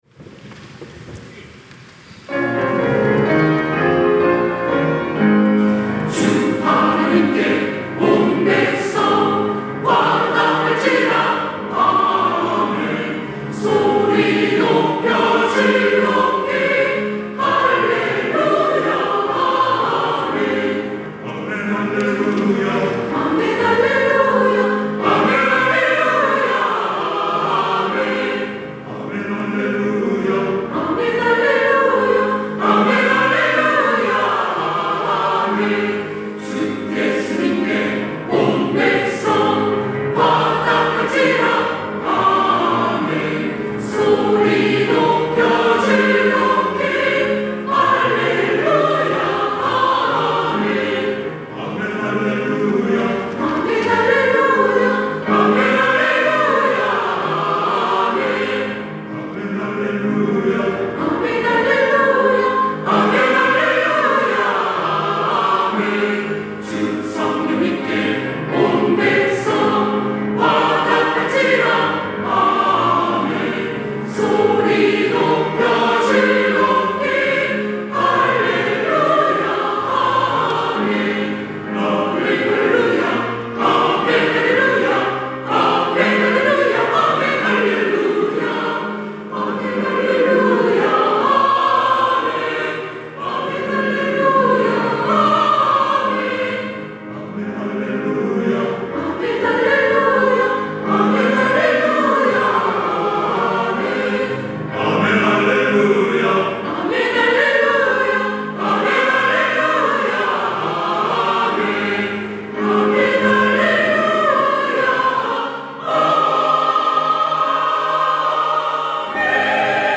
시온(주일1부) - 아멘 할렐루야
찬양대